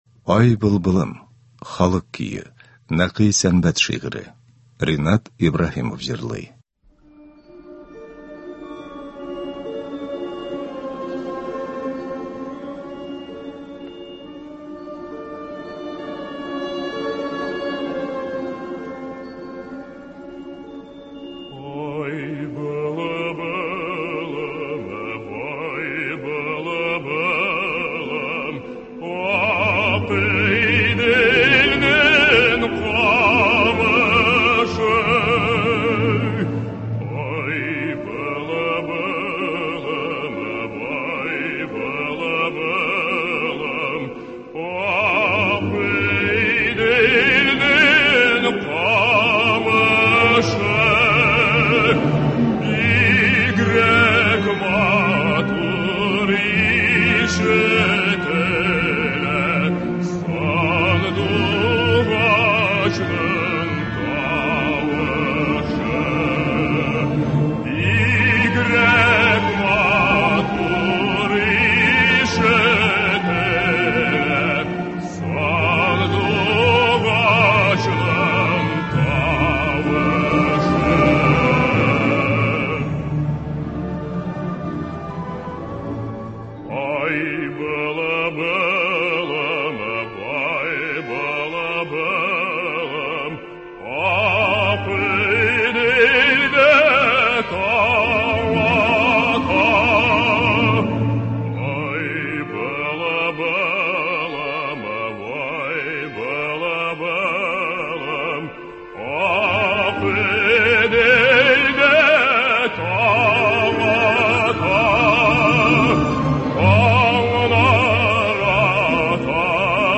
Эстрада концерты.